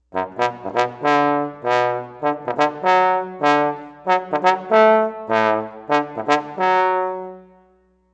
trombone.mp3